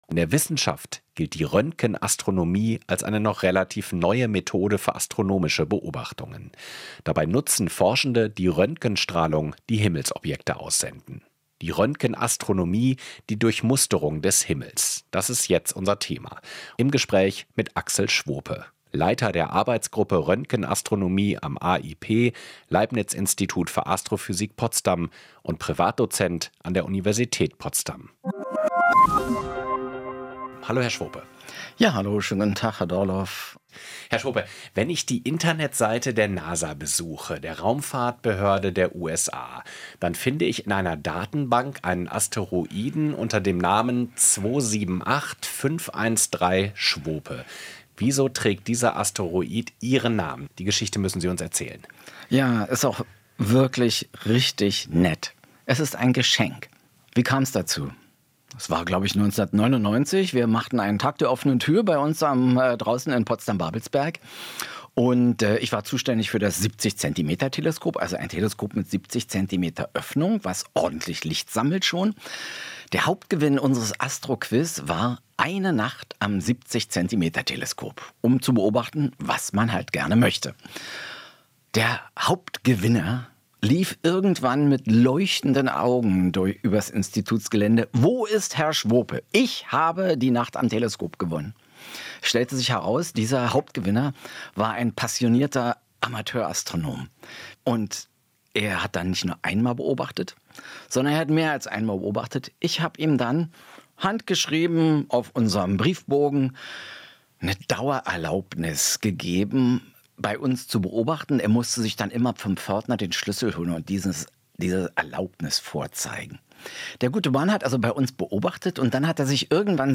Interviews und Gesprächsrunden